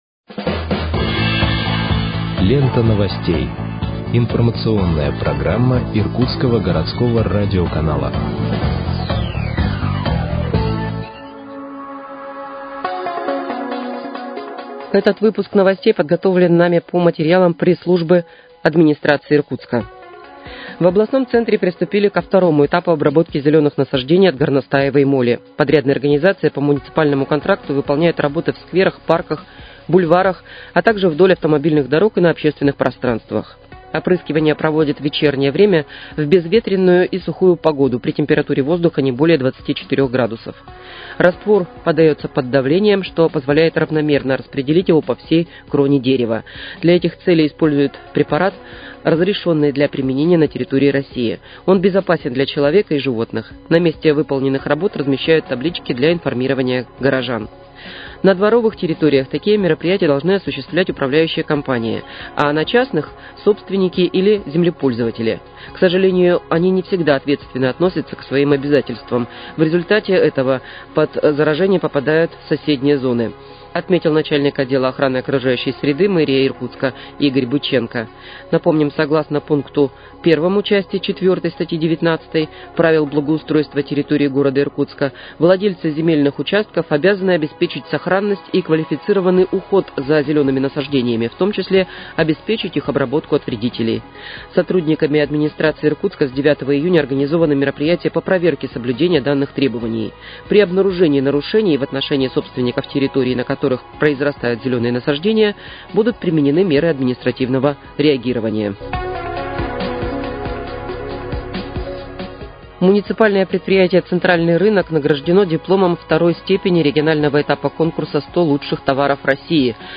Выпуск новостей в подкастах газеты «Иркутск» от 16.06.2025 № 2